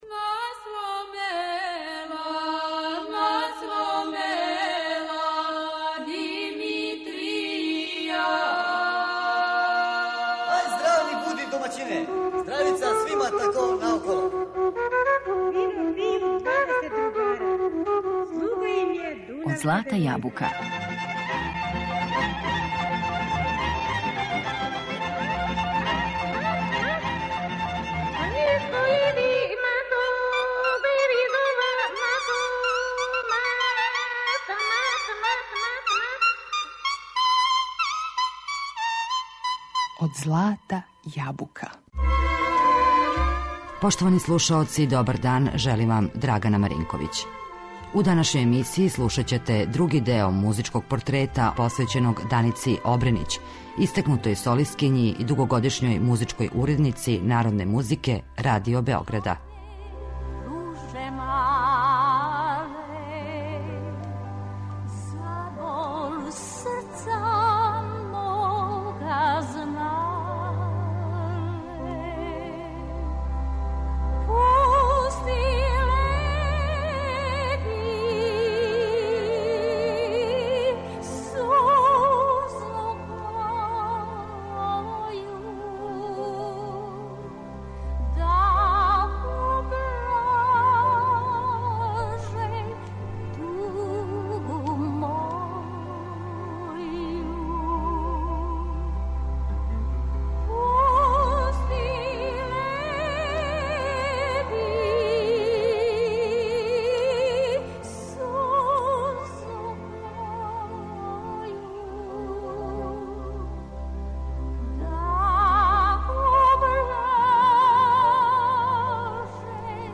Била је једна од најоригиналнијих и најпознатијих интерпретатора врањанских и староградских песама.